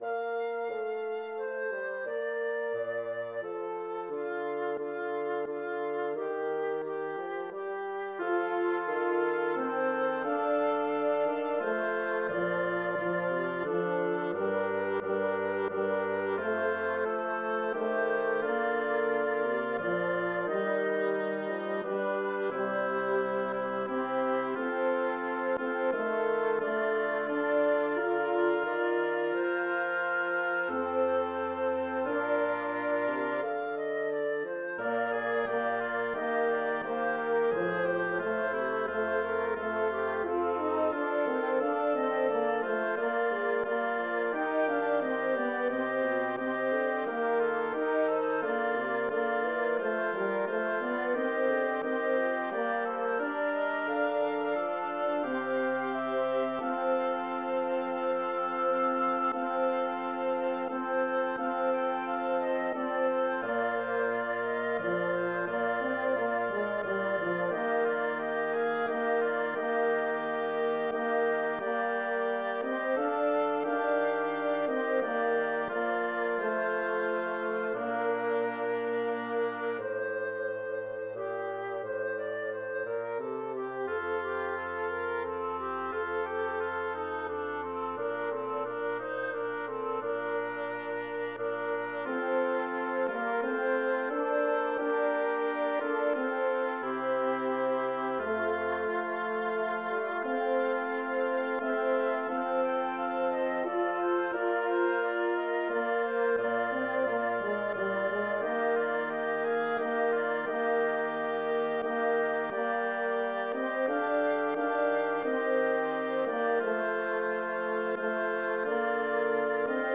Number of voices: 2vv Voicing: SS Genre: Sacred, Motet, Votive antiphon
A SATB a cappella arrangement of the two-voice with continuo original.
Key signature (in original one flat with e-flat added as accidentals) here normalized to B-flat major.